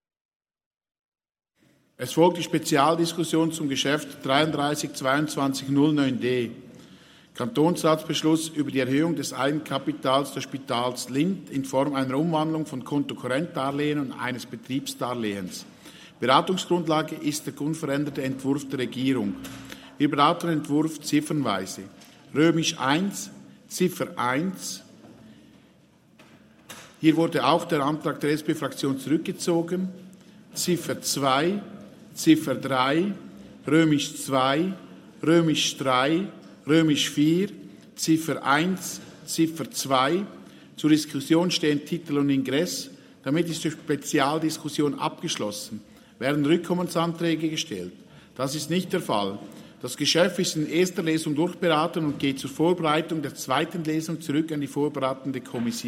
Jäger-Vilters-Wangs, Ratspräsident: Die Vorlage ist in erster Lesung durchberaten und geht zur Vorbereitung der zweiten Lesung zurück an die vorberatende Kommission.